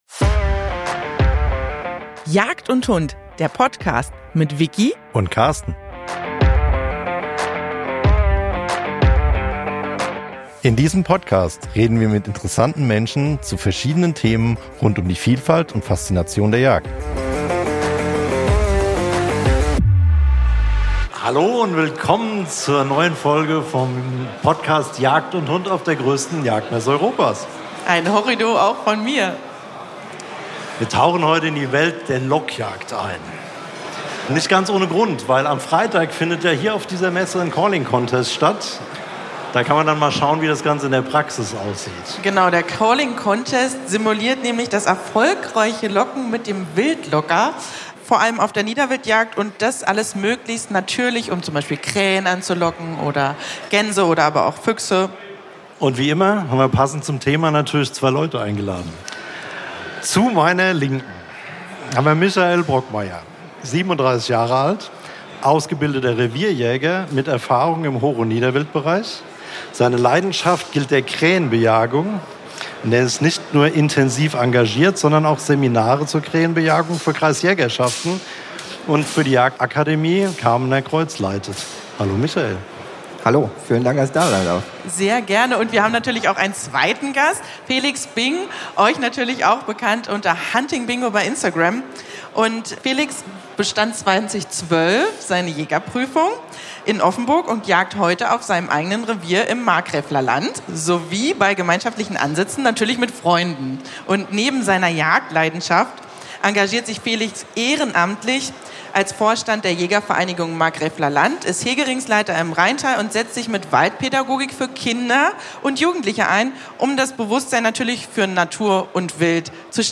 Vielfalt der Jagd - Lockjagd Livefolge von der Messe 2025 ~ JAGD & HUND Podcast